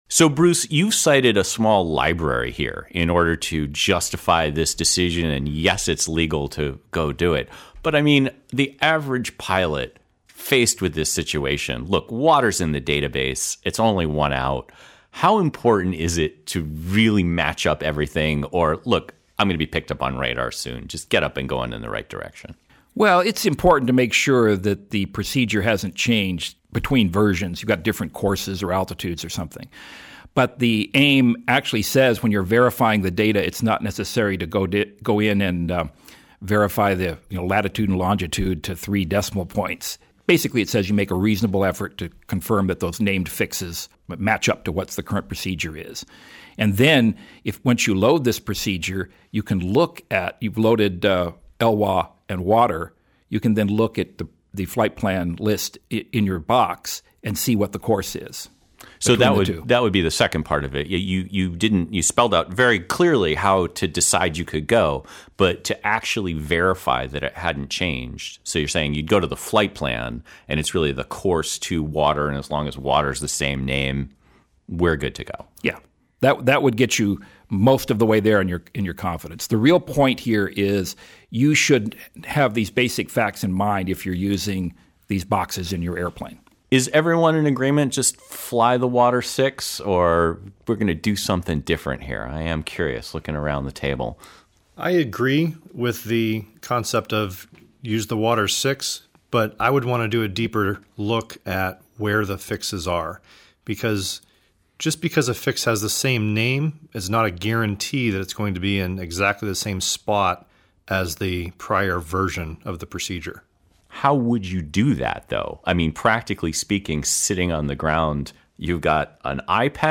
Departing_under_WATTR_roundtable.mp3